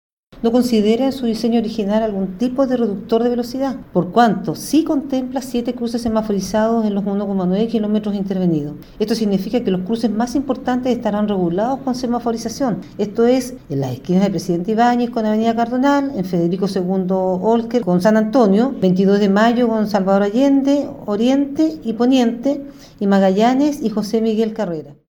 A su vez, la directora del Servicio de Vivienda y Urbanización (Serviu), Isabel de la Vega, sostuvo que pese a no haber reductores de velocidad, se contará con diferentes cruces semaforizados.